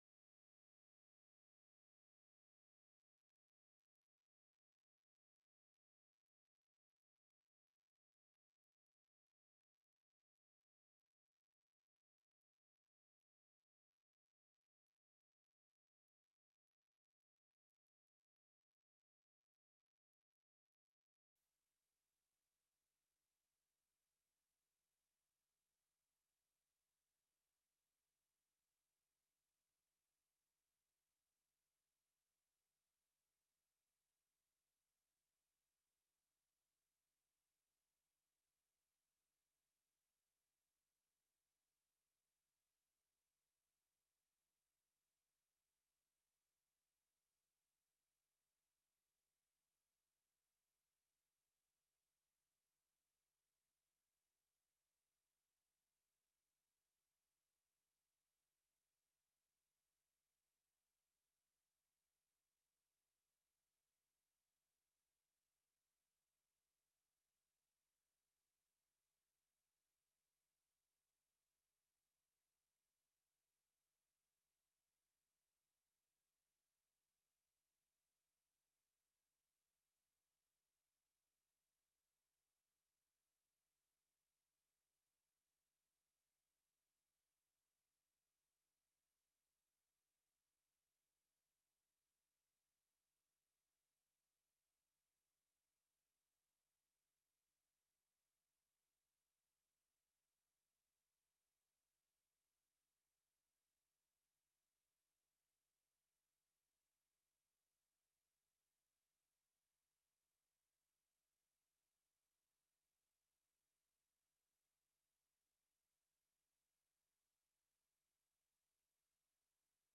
Holy Eucharist Service on March 17, 2024
Please enjoy this recording of our Green Growing Sunday service on the Fifth Sunday in Lent.